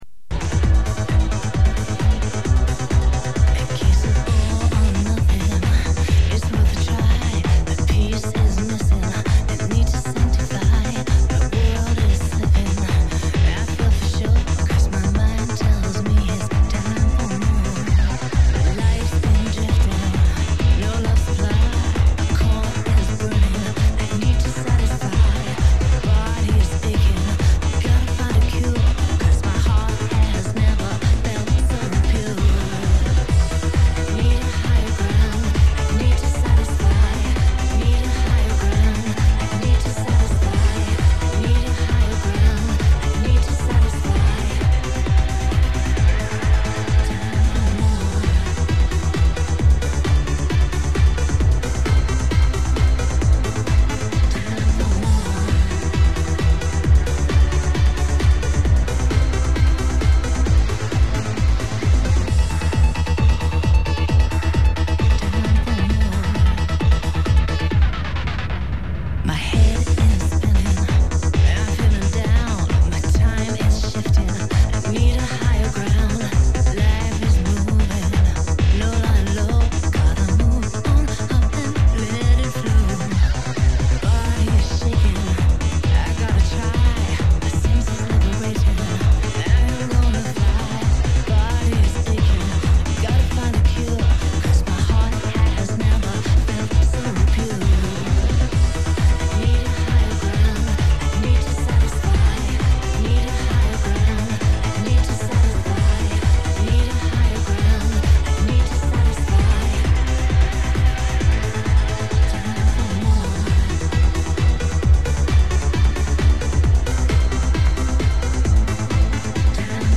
Запись сделана с европы плюс.